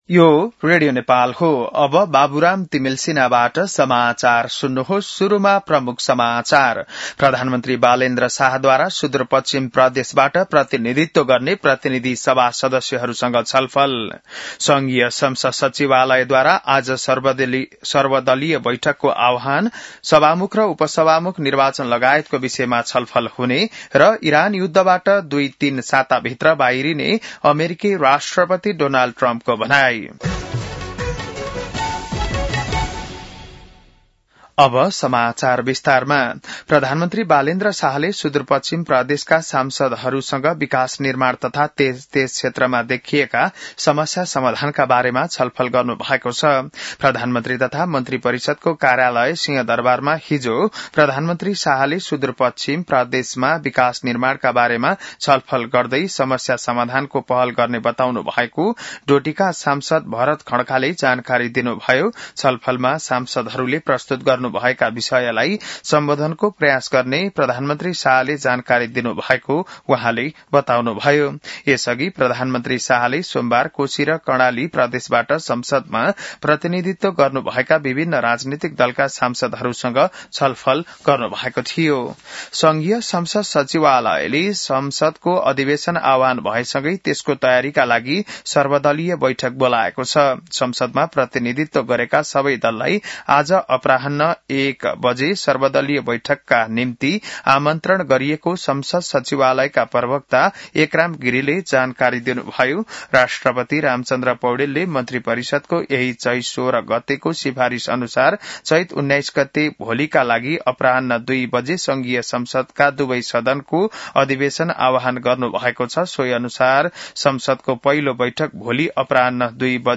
बिहान ९ बजेको नेपाली समाचार : १८ चैत , २०८२